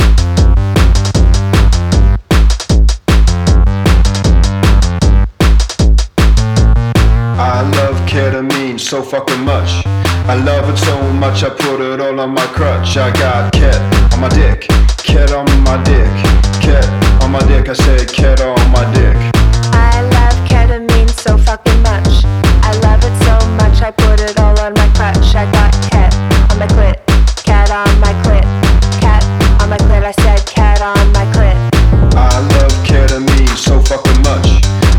Жанр: Танцевальные / Электроника / Техно